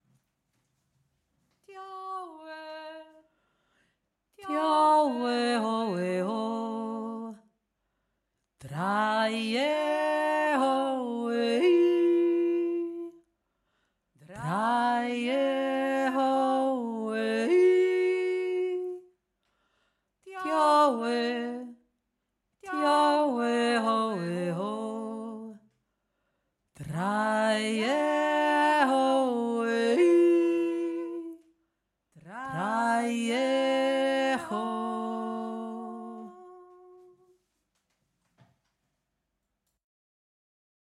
Der Kanon
zweite Stimme
dio-e-zweite-stimme.mp3